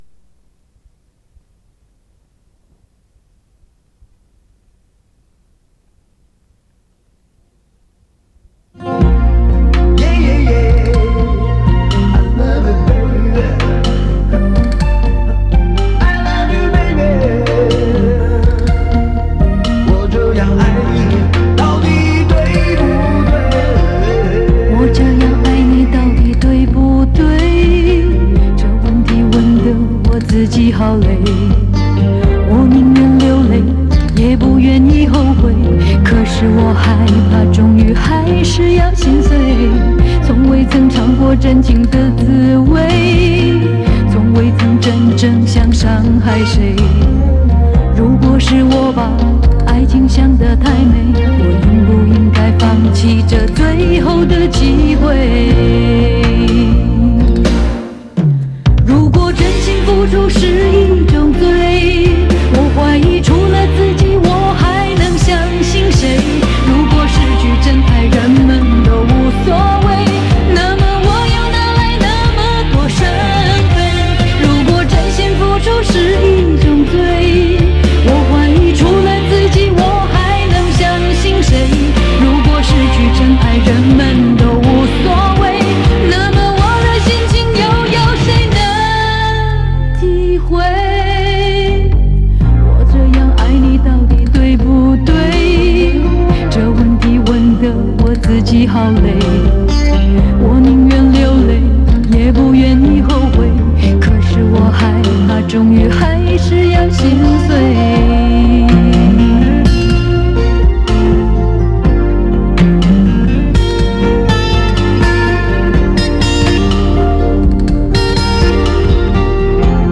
磁带数字化：2022-10-29